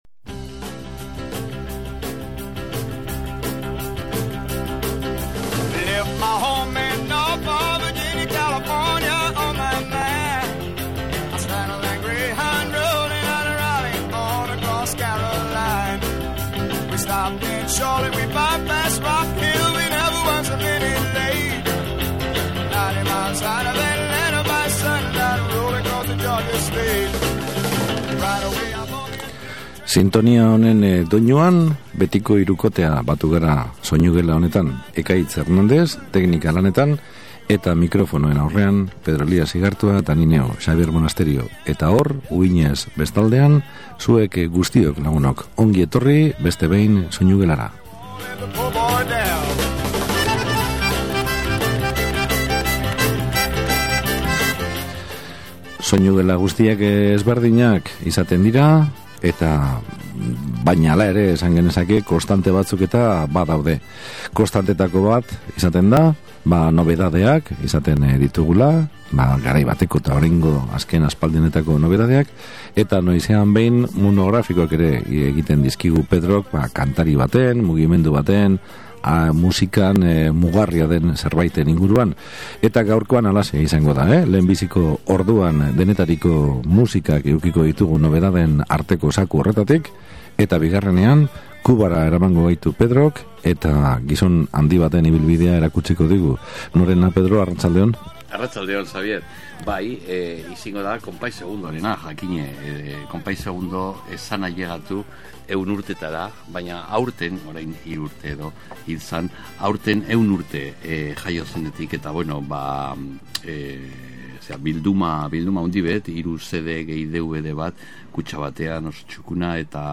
denetariko musikak izan ditugu
Aljeriako emakume kantaria
musika andalusia